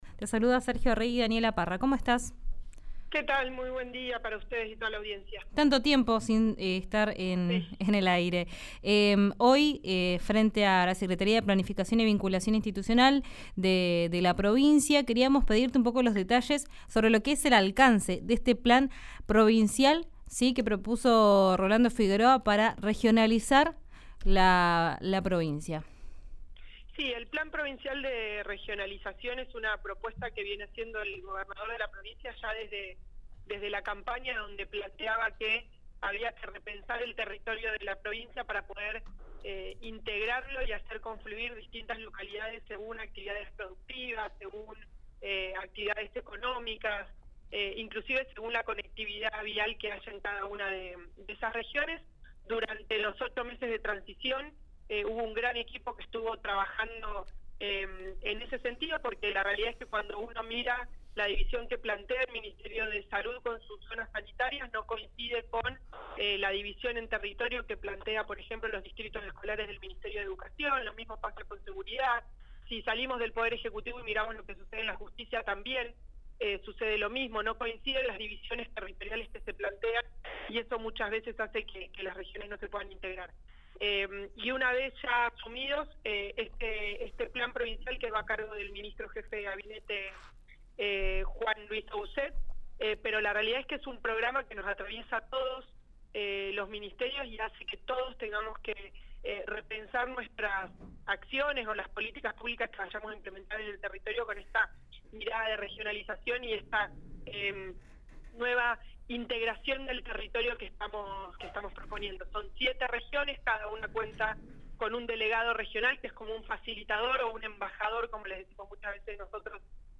Escuchá a Leticia Esteves en RÍO NEGRO RADIO: